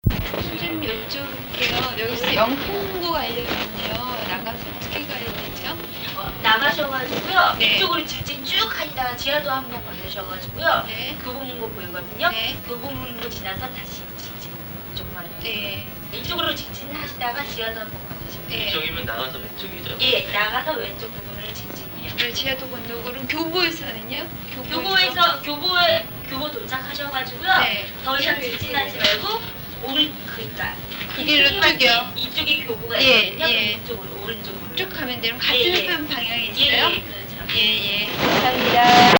RN37track01_ Real conversation_1_for_PTs1and4.mp3 (921.05 KB)